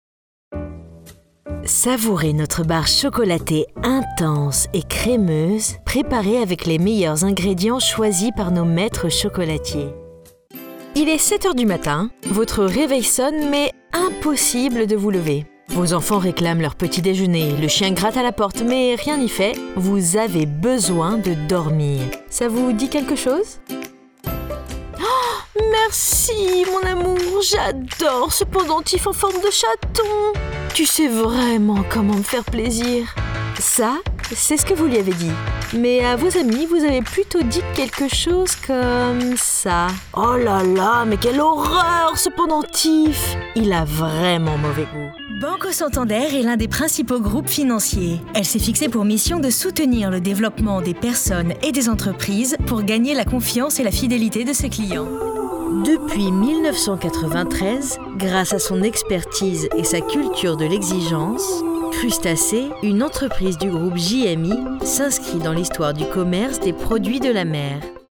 I'm a French voice over artist originally from Paris. Therefore, my accent can be defined as standard French or Parisian.
My voice is young, friendly and dynamic.
Sprechprobe: Werbung (Muttersprache):